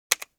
开关.wav